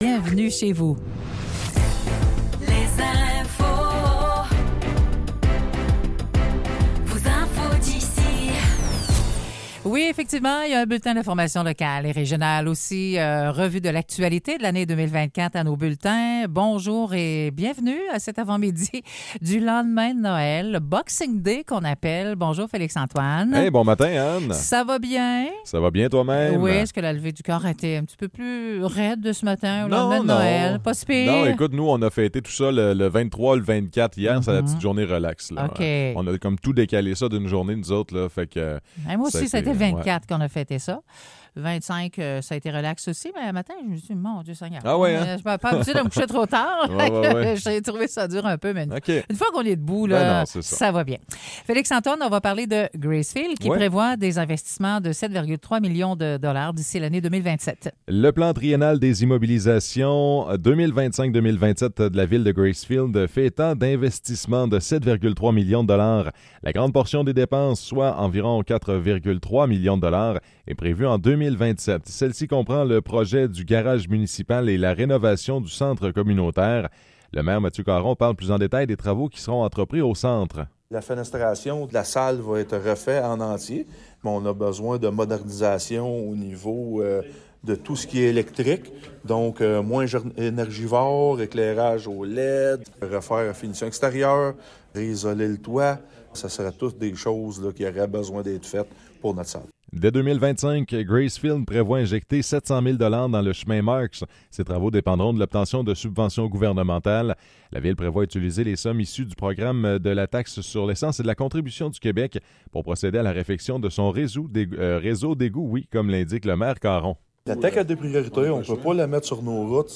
Nouvelles locales - 26 décembre 2024 - 8 h